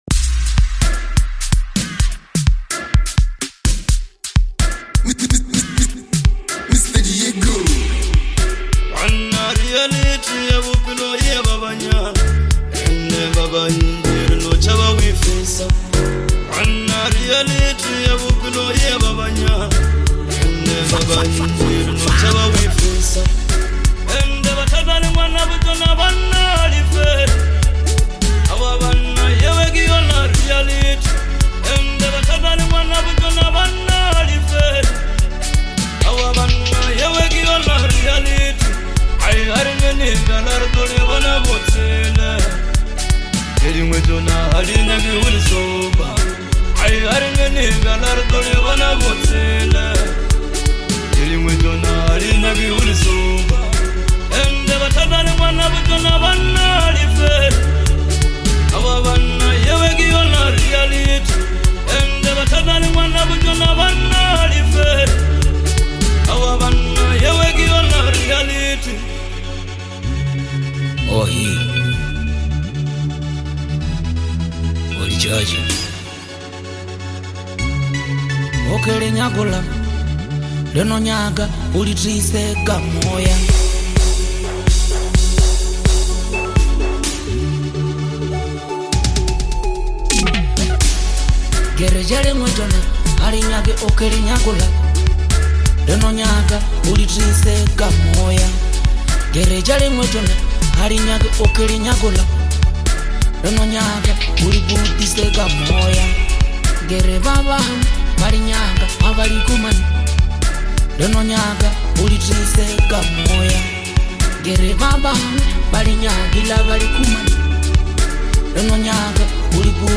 a bold and energetic anthem